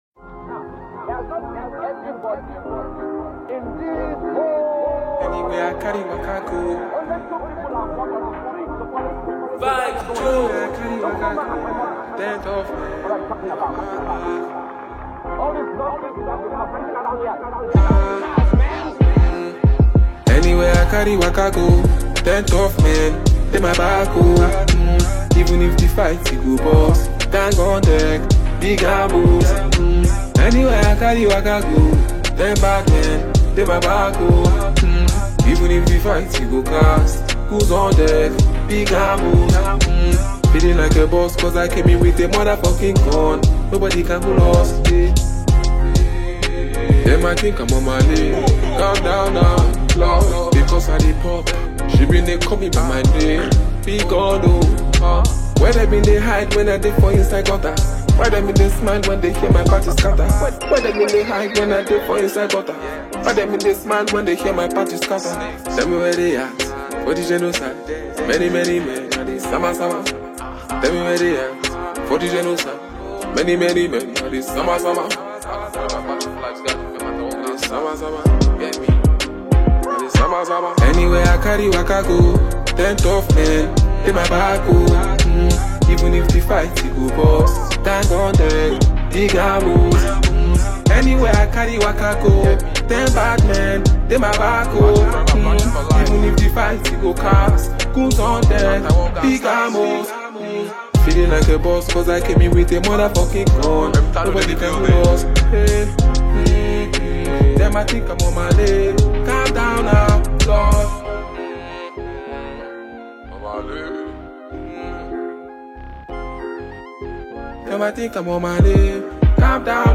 A heavyweight Nigerian rap genius